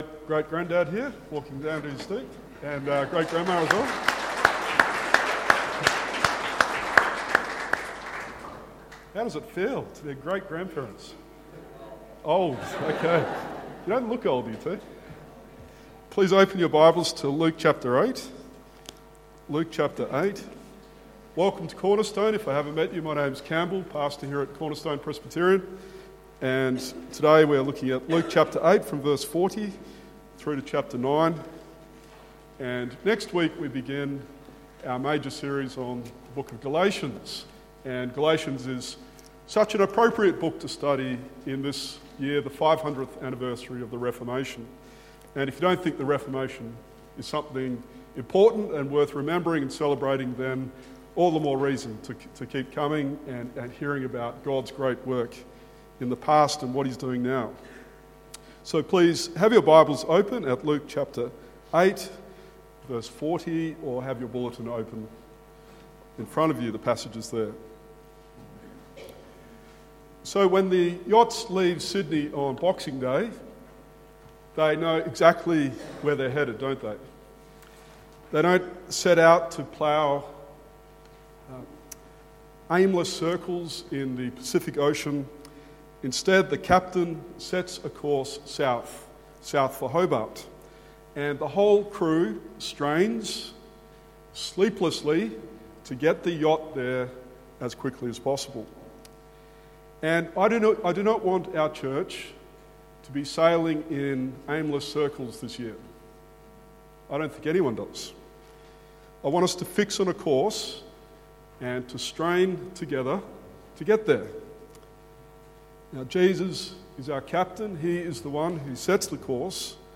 Download Sermon Series: Gospel of Luke